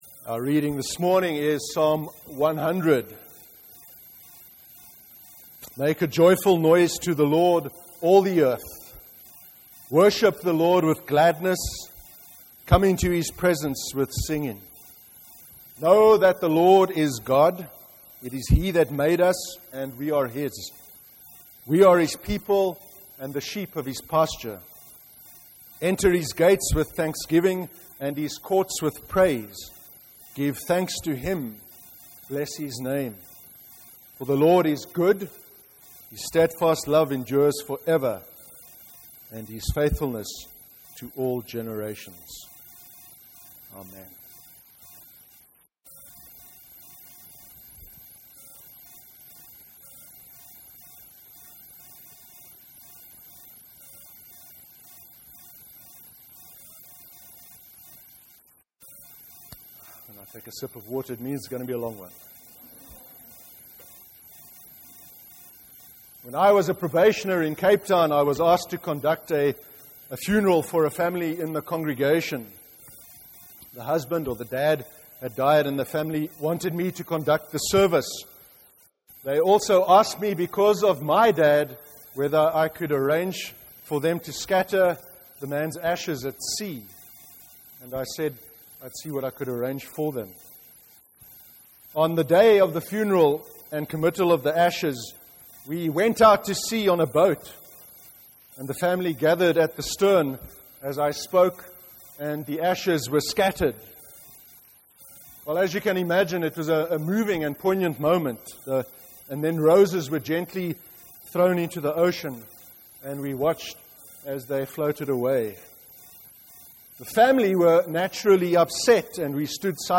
31/05/2015 sermon: How worship forms and shapes us as disciples